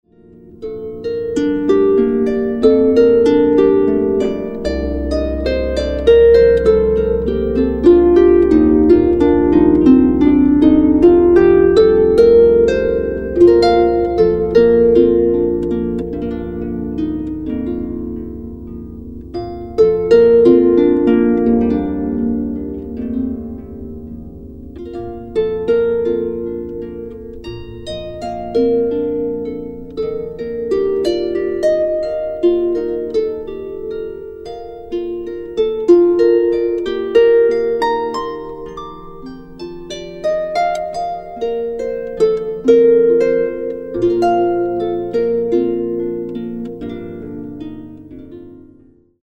Gesang, keltische Harfen
Violine
Violoncello
Fiddle
Pekussion
Bodhran